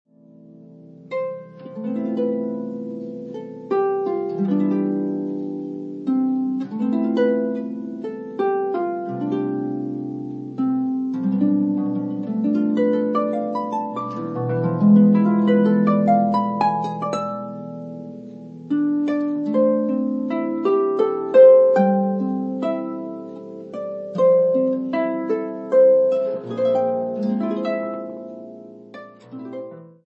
beautiful harp music